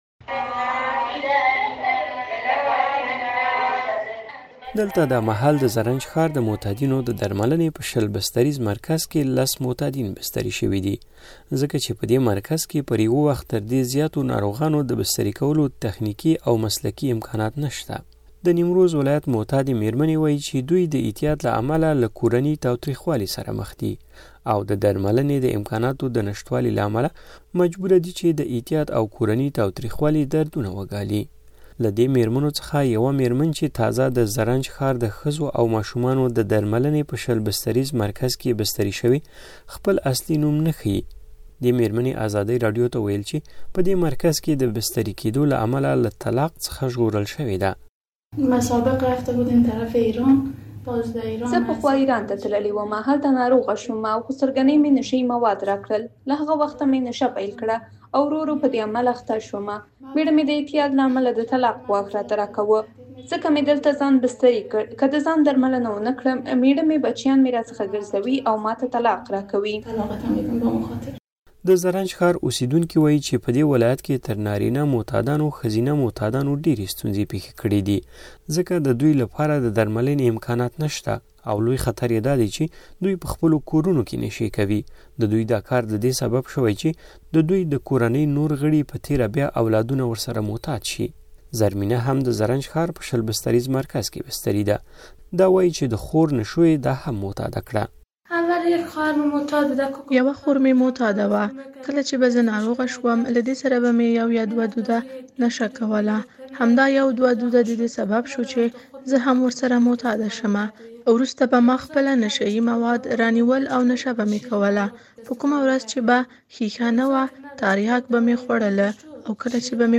د نیمروز راپور